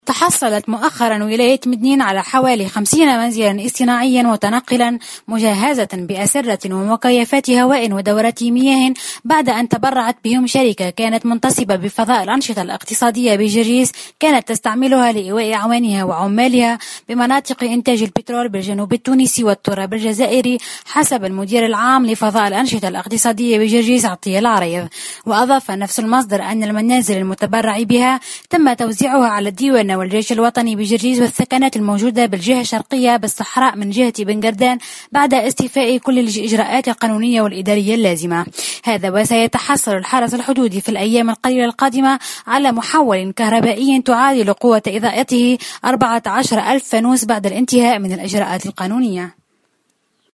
مراسلتنا في جرجيس